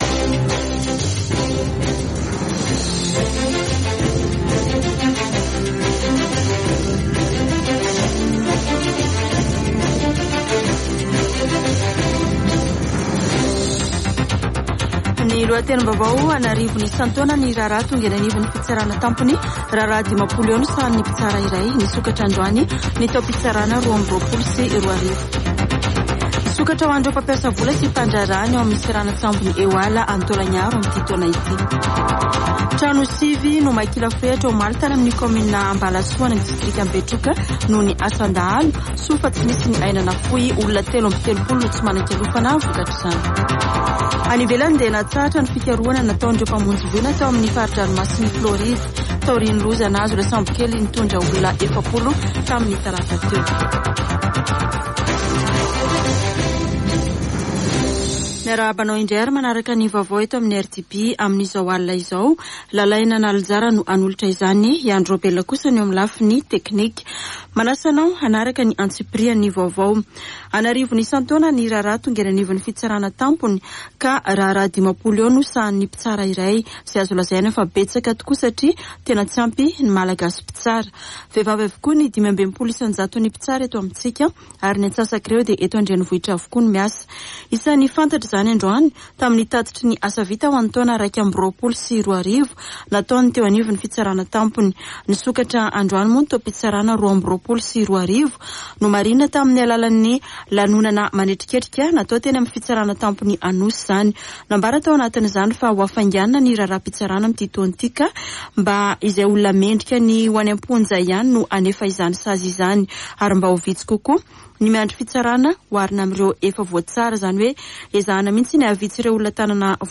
[Vaovao hariva] Zoma 28 janoary 2022